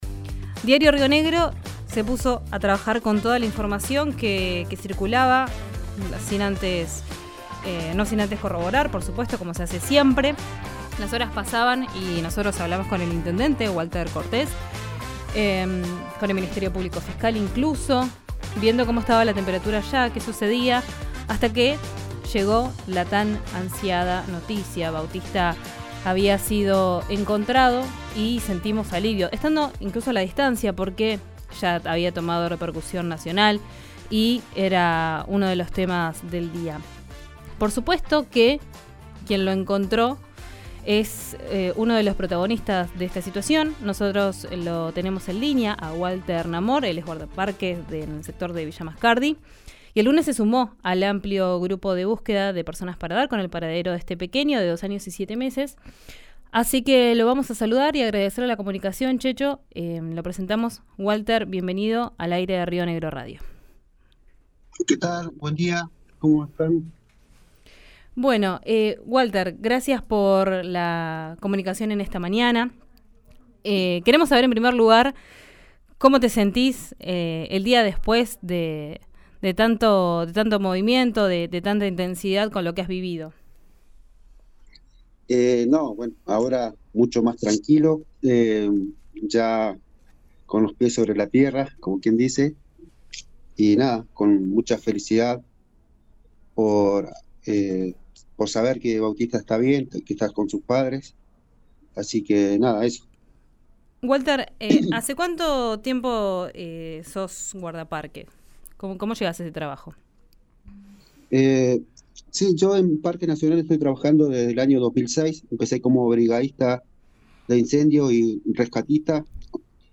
Escucha su relato en RÍO NEGRO RADIO: